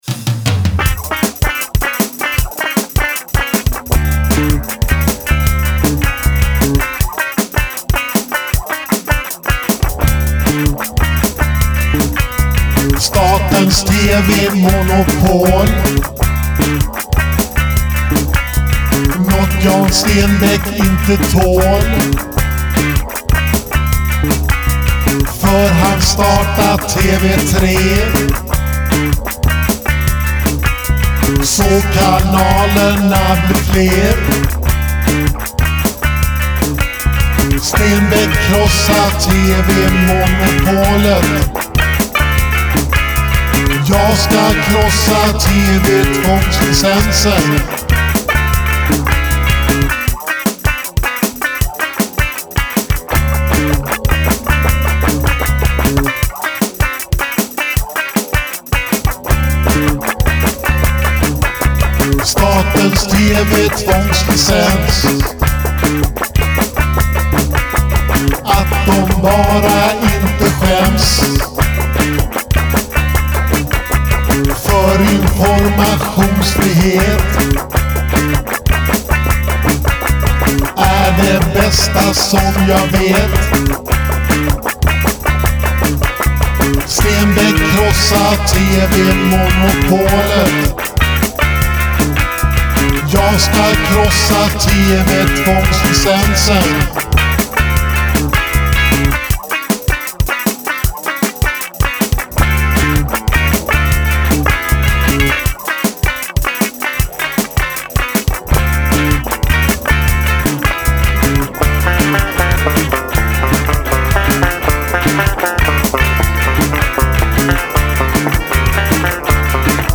Baseb blev funkig oktavbas.